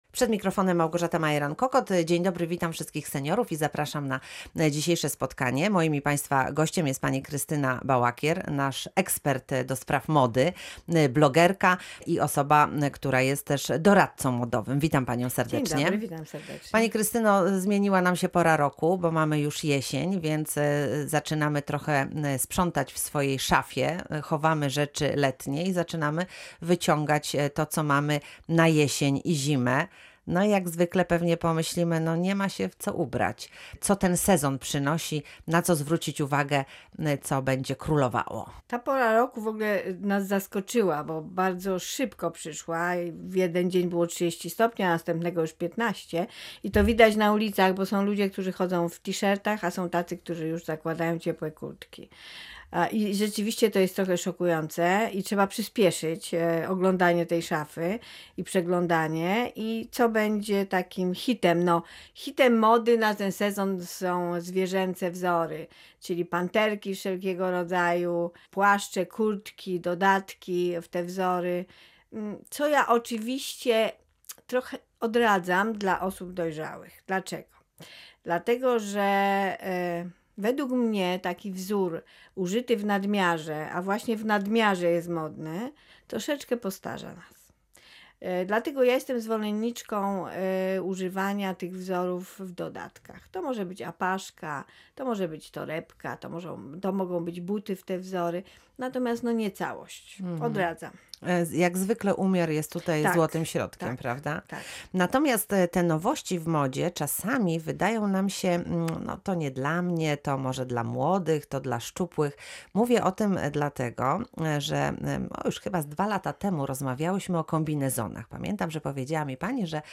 Dodatkowo będziecie mogli wysłuchać tutaj audycji z moim udziałem w Radio Wrocław, która była nadawana w sobotę.